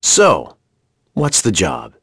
Crow-vox-get.wav